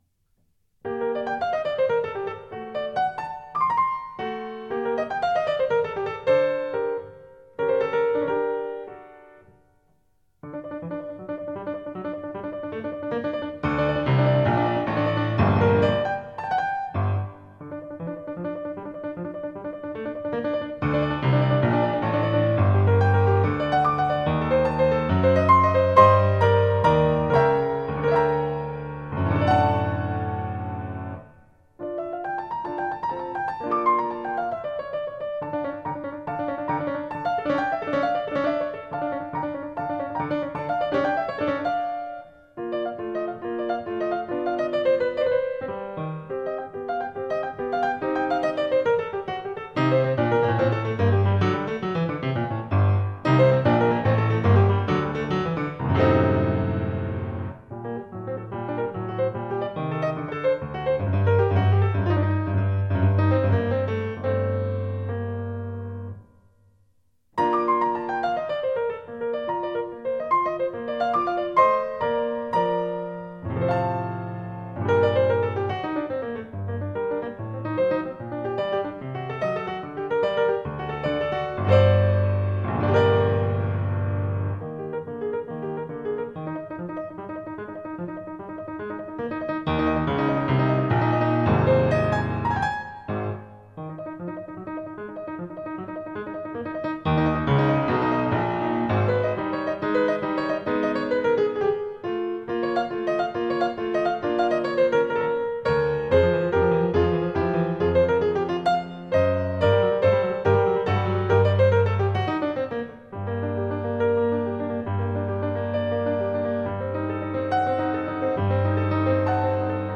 Ce sont des morceaux de virtuosité, pleins de contrastes, et on peut imaginer à les entendre que notre Marie-Anne était une personne vive et séduisante, si tant est que ces portraits musicaux soient ressemblants.